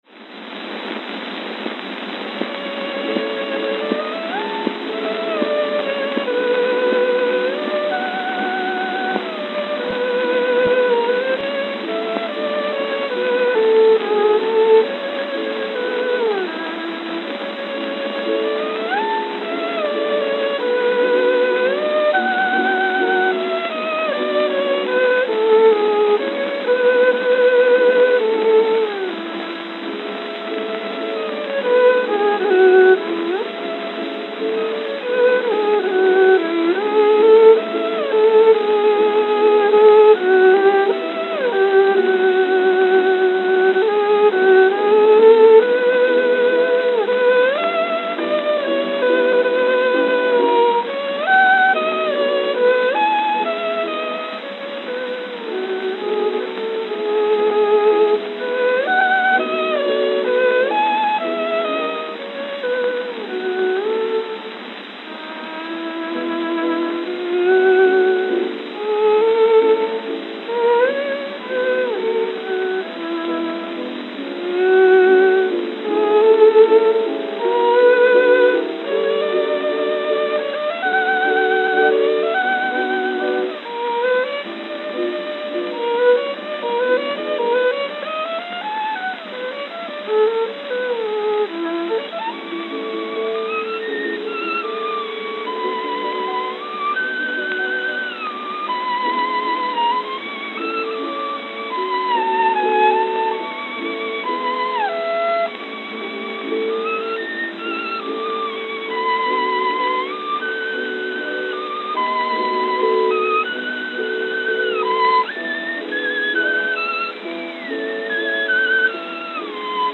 It was a prestigious tier featuring High Classical and Operatic selections with a base price of $2.00. It was analogous to Victor's Red Seal Label and Columbia's Symphony Series.
Albert Spalding Albert Spalding
New York, New York New York, New York
Note: Worn.